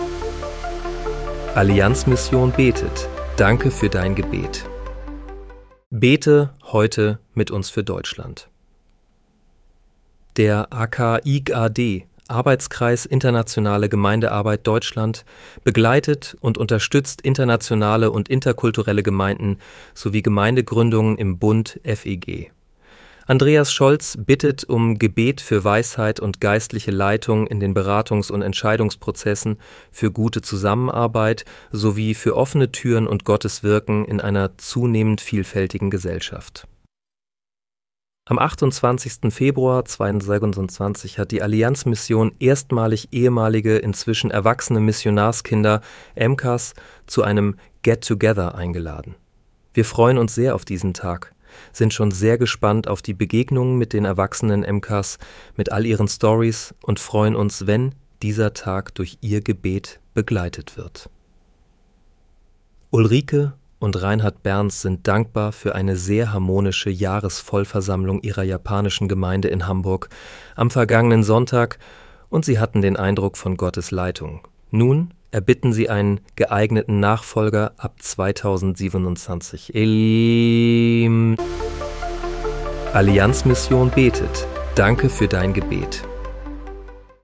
Bete am 28. Februar 2026 mit uns für Deutschland. (KI-generiert mit